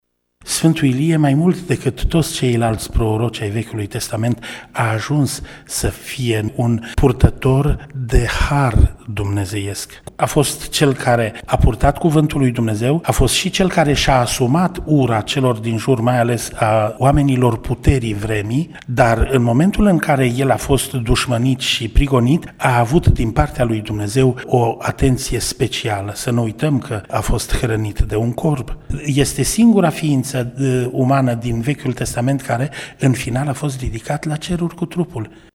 spune preotul ortodox din Tg Mureş